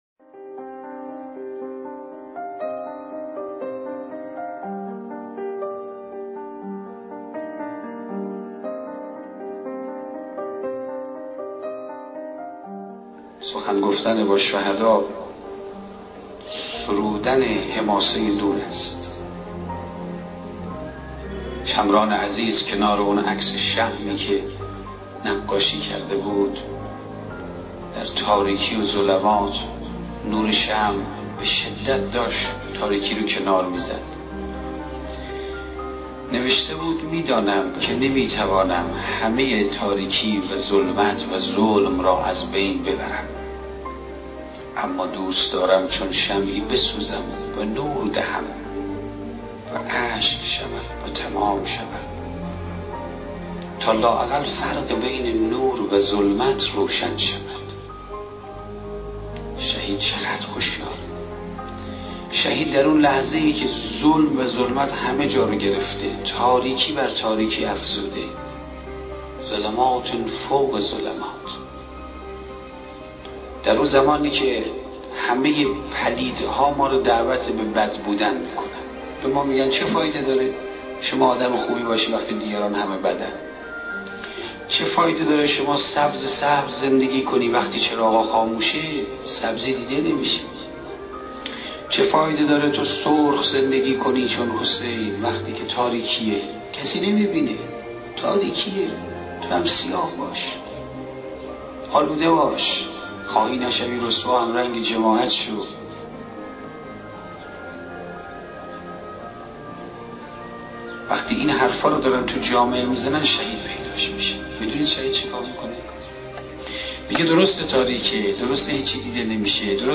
صوت روایتگری